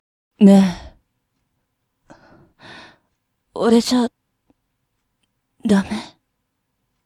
セリフ
1番目の台詞は、好きな子に彼氏がいても、お色気でアタックする感じです。
色気でアタック！を念頭になけなしの色気を振りまいてすがってみました。